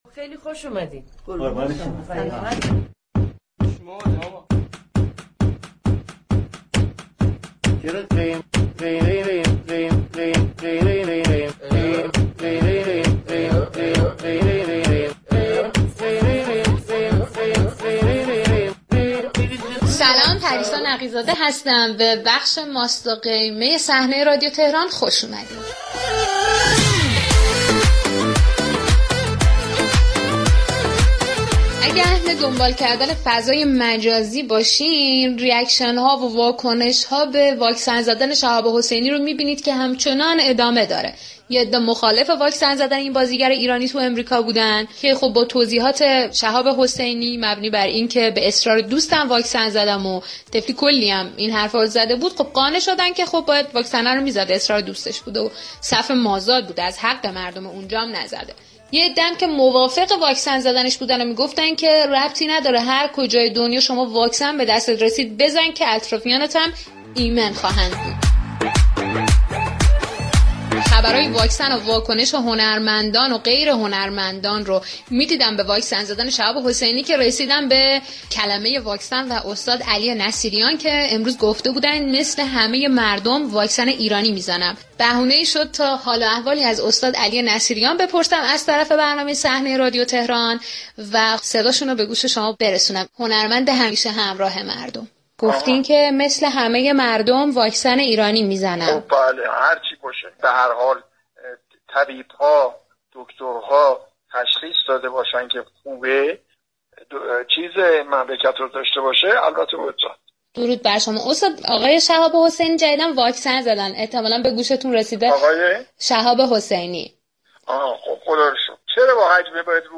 به گزارش پایگاه اطلاع رسانی رادیو تهران، گفتگویی با استاد علی نصیریان هنرمند پیشكسوت تئاتر، سینما و تلویزیون در برنامه صحنه 5 اسفندماه رادیو تهران پخش شد.